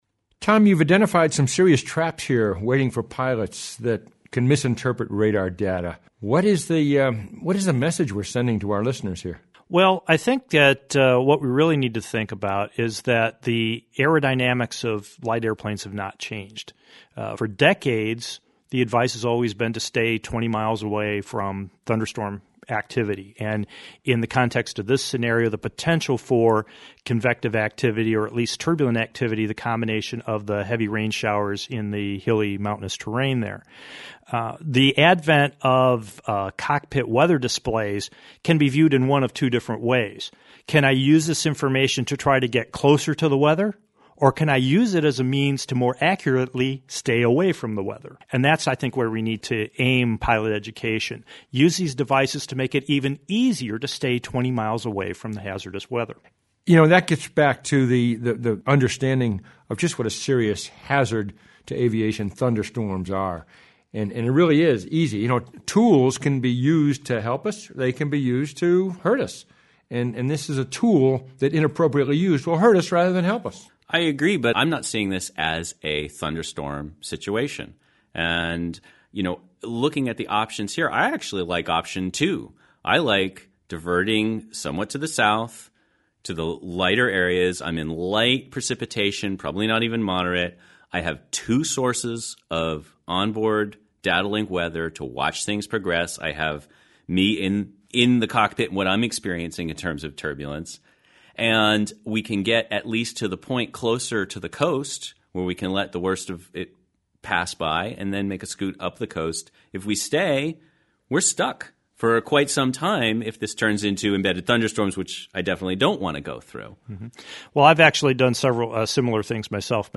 yellows_not_that_bad_roundtable.mp3